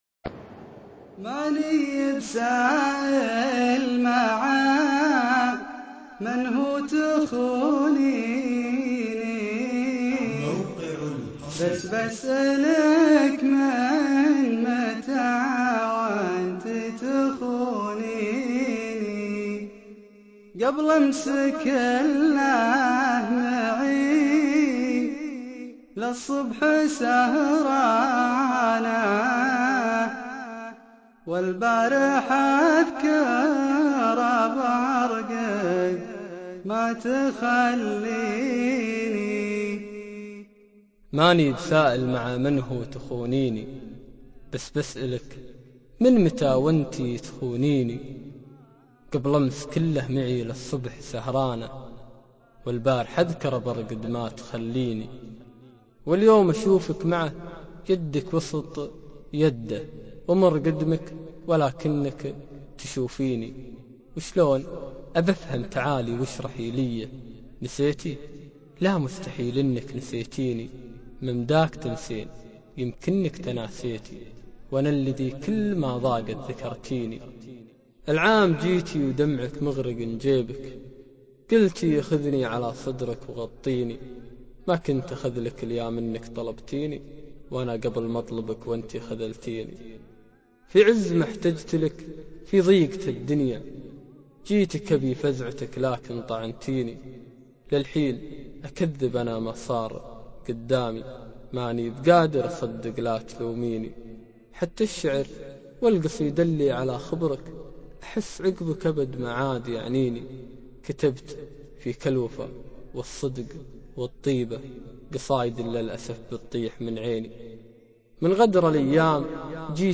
شيله + إالقاء